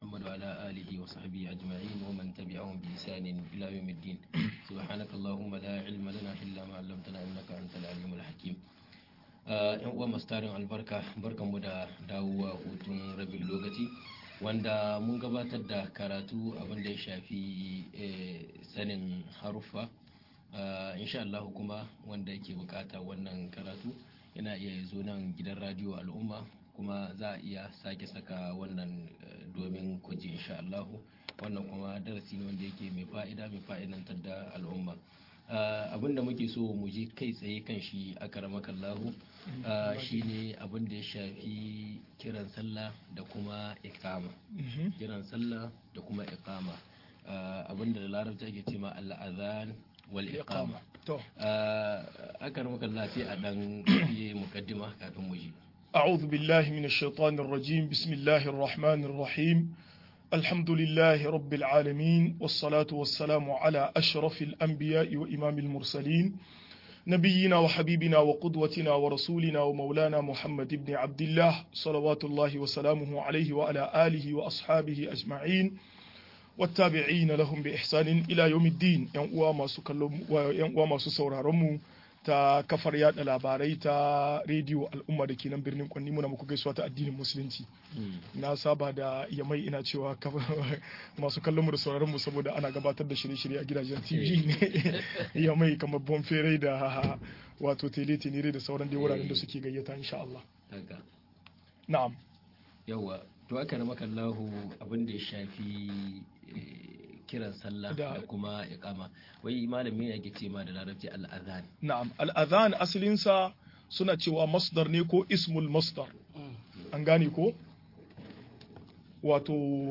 Yadda ake kiran sallah - MUHADARA